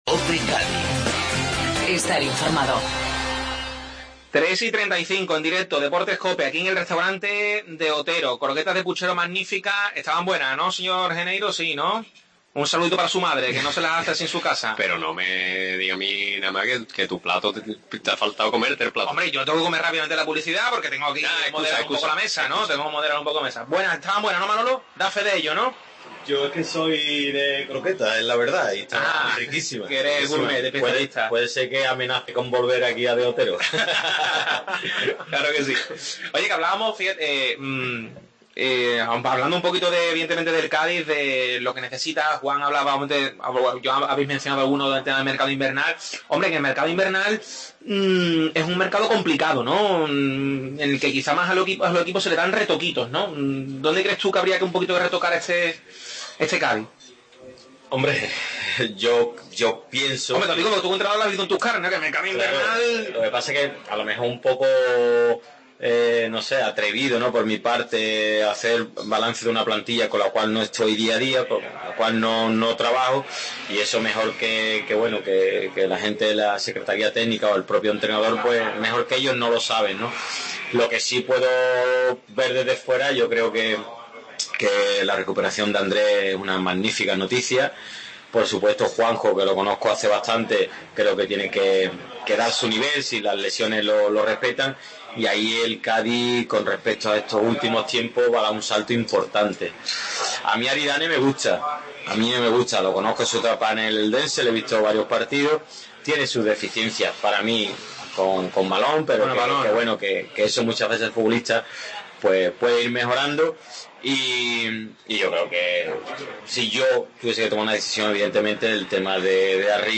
AUDIO: Segunda parte desde el Restaurante De Otero de la tertulia de los martes